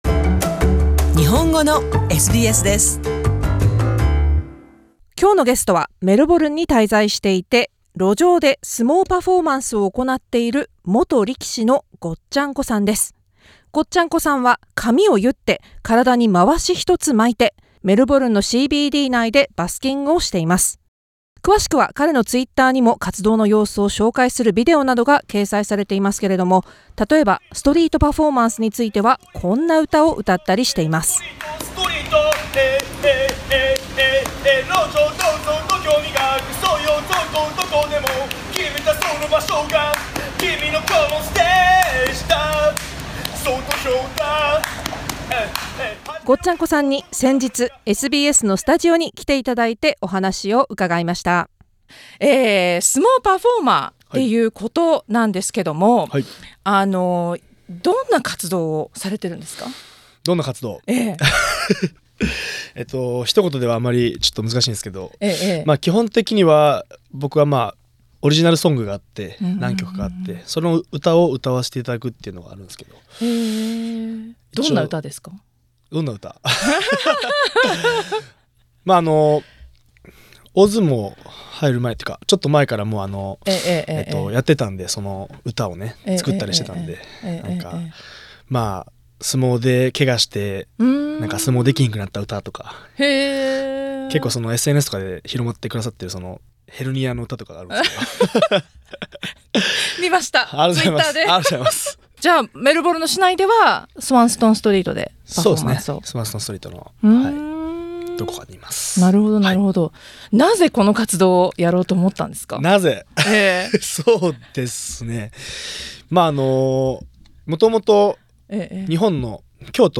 なぜ町に出てオリジナルソングを歌いながら生活するようになったのか、力士としての一日とは？メルボルンのスタジオでお話を伺いました。